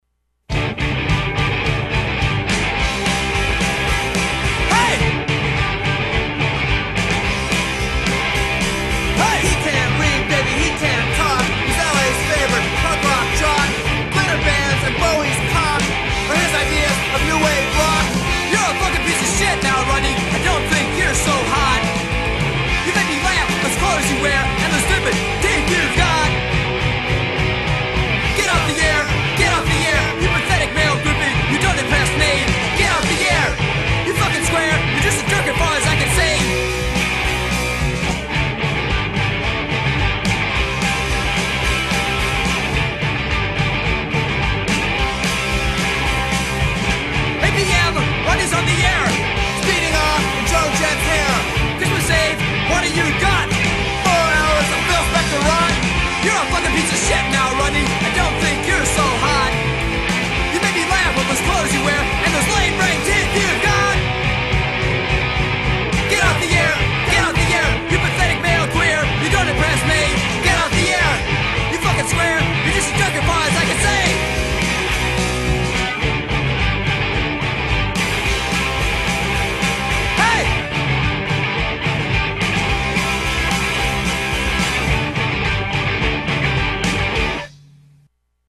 punker band